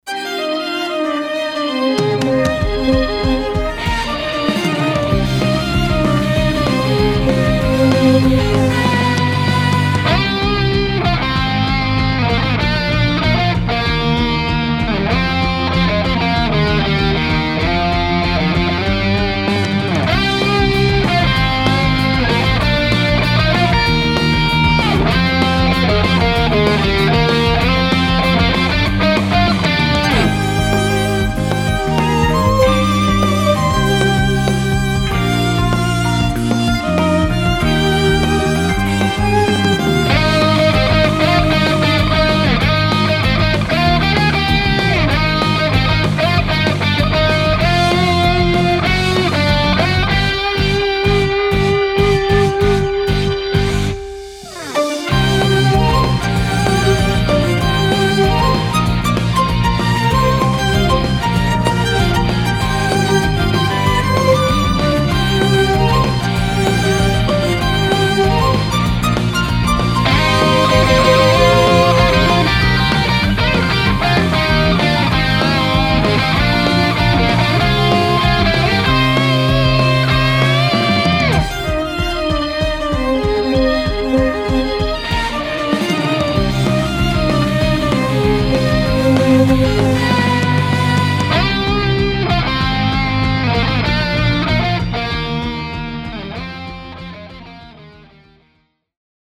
フリーBGM バトル・戦闘 バンドサウンド